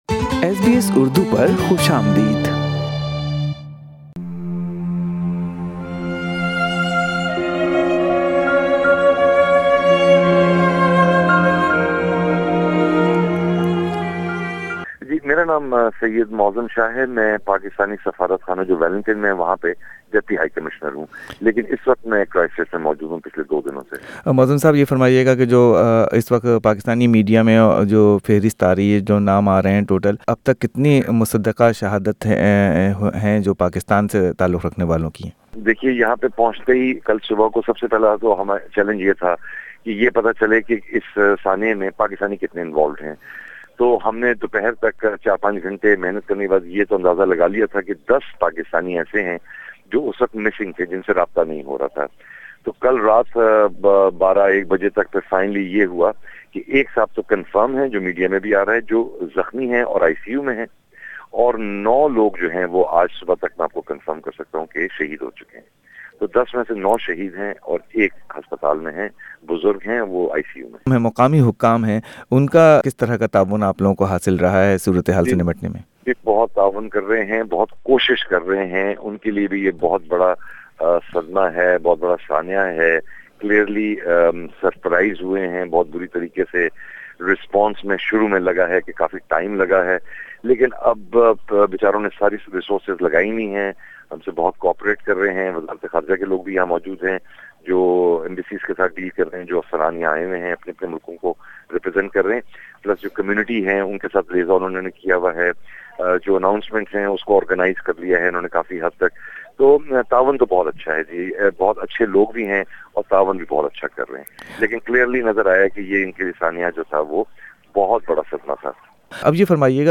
کرائیسٹ چرچ کے دہشت گرد حملوں کے متاثرین کی مدد کے لئیے پاکستانی سفارتخانے نے ہنگامی بنیادوں پر کام کیا ہے۔ نیو زی لینڈ میں پاکستان کے ڈپٹی ہائی کمشنر سید معظم شاہ کی ایس بی ایس اردو سے کی گئی بات چیت سنئیے